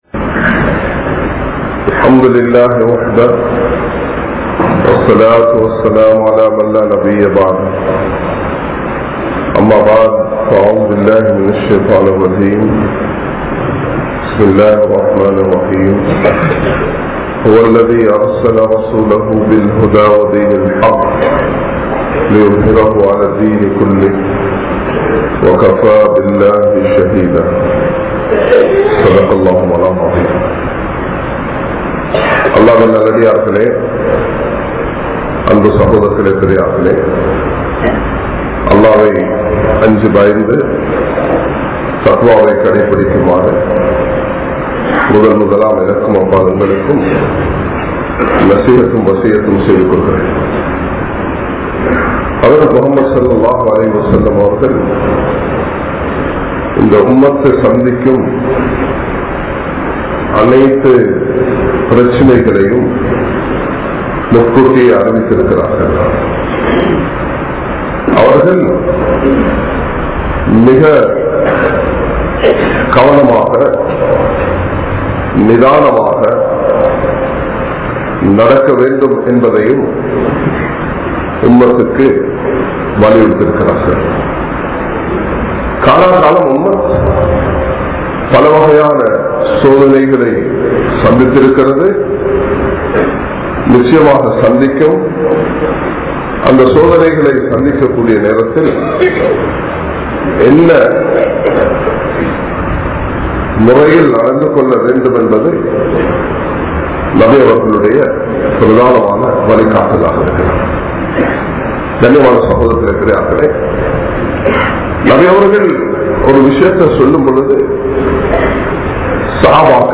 Indru Muslimkal Padum Soathanaihal | Audio Bayans | All Ceylon Muslim Youth Community | Addalaichenai